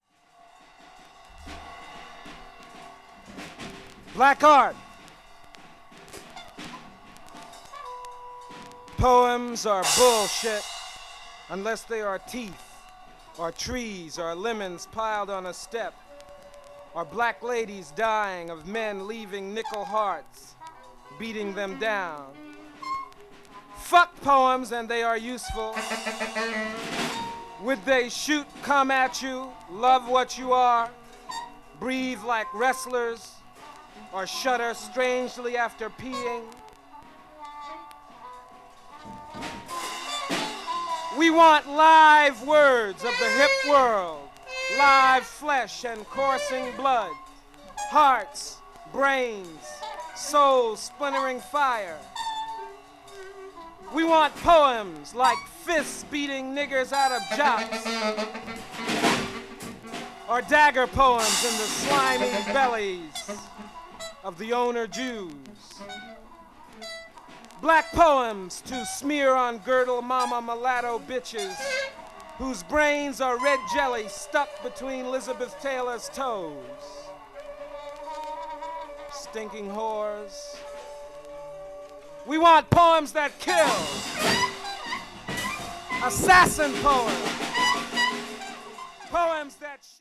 avant-jazz   free improvisation   free jazz   spiritual jazz